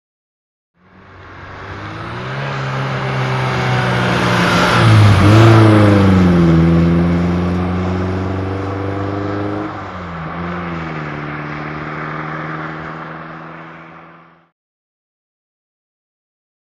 Automobile; By; Audi 80l Up, Changes Down At Mic. And Pulls Away.